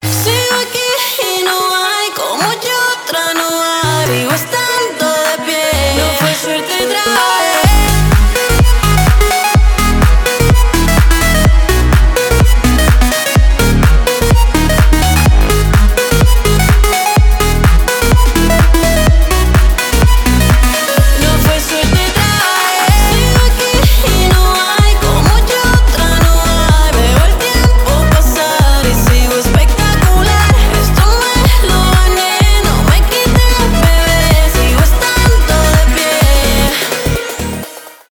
progressive house
зажигательные , edm , танцевальные